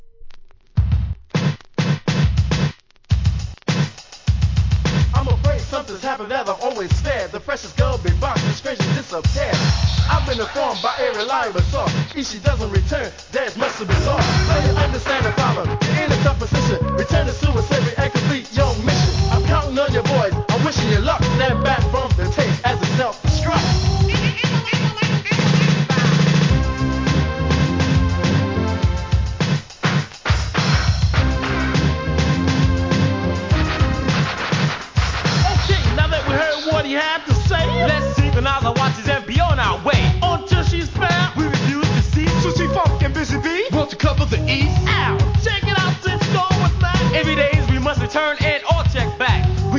HIP HOP/R&B
b/wはヒューマンビートボックスにイナタイスクラッチ！！